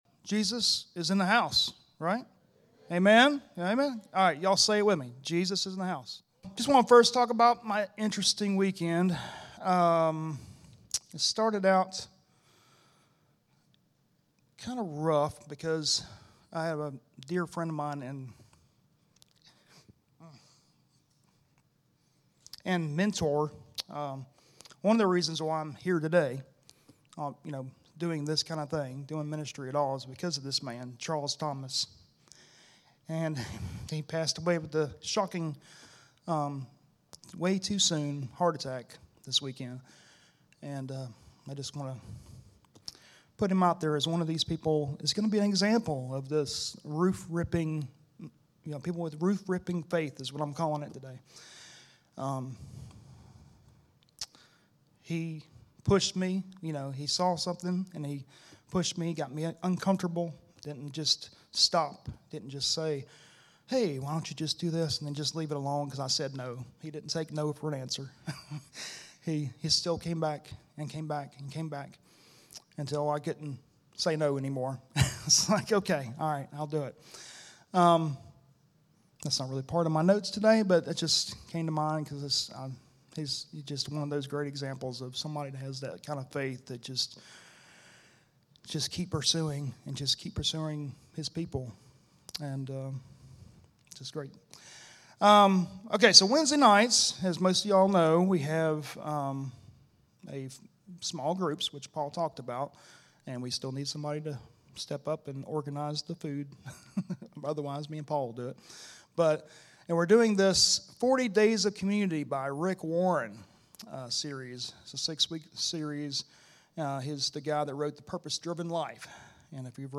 Series: Stand Alone Sermon